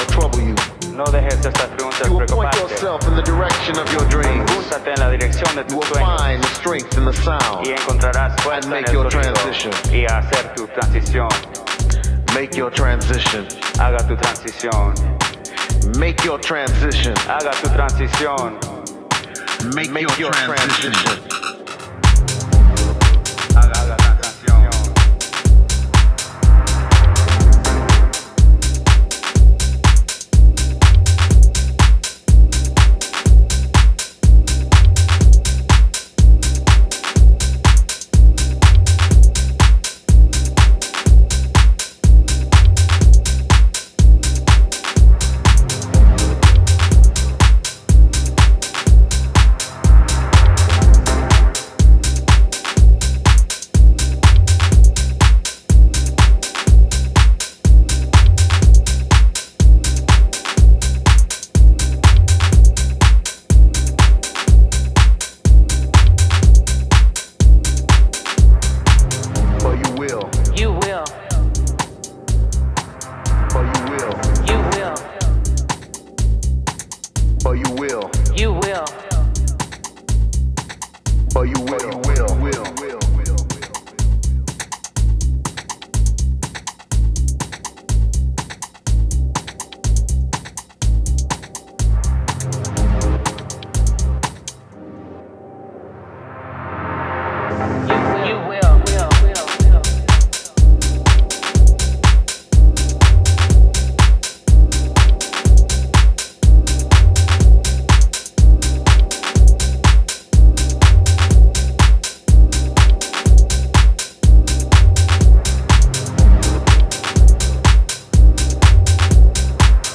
groovy and timeless